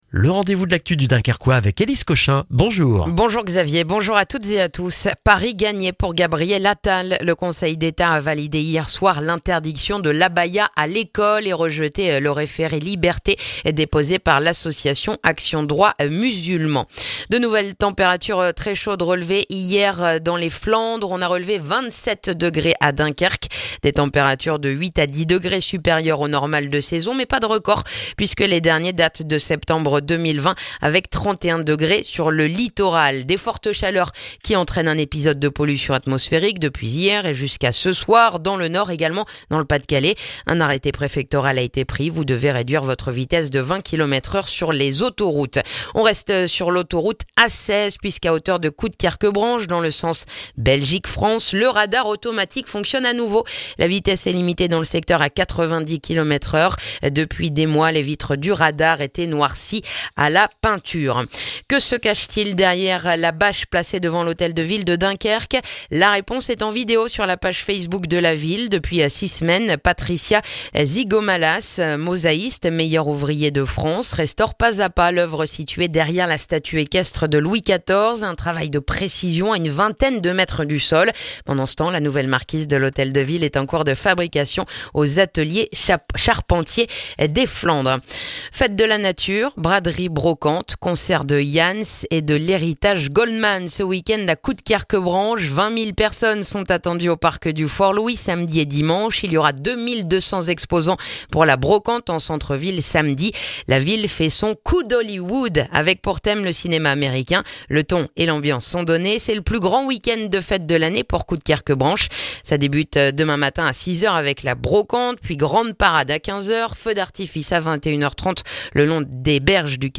Le journal du vendredi 8 septembre dans le dunkerquois